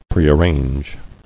[pre·ar·range]